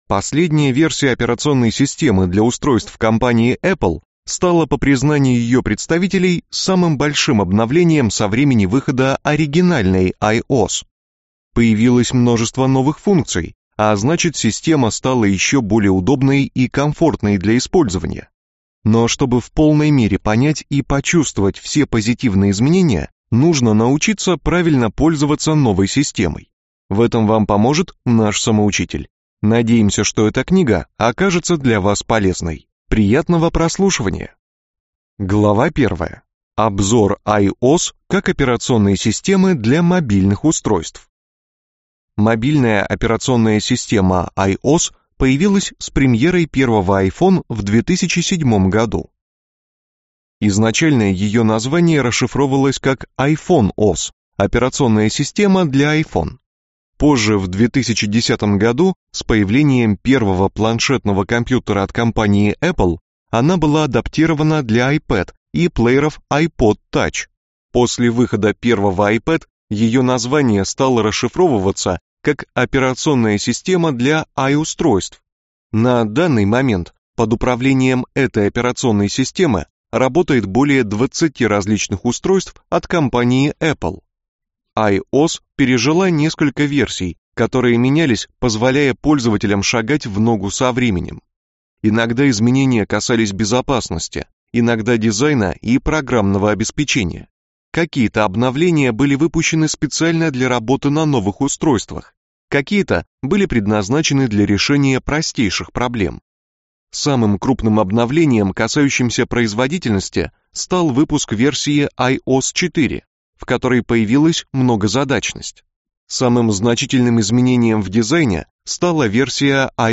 Аудиокнига Самоучитель IOS 8 | Библиотека аудиокниг
Прослушать и бесплатно скачать фрагмент аудиокниги